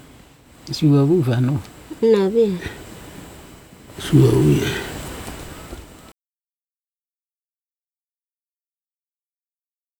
Its a simple drag and drop application which analyses your recording and brings the speech throughout it up to the same level. I tried it out on a recording where I had three informants talking, and one of them whispered his way through it.
The third person speaking is the one who whispered his way through the session.
Obviously, the background noise and hiss goes up with each amplified section, but you can’t complain about that!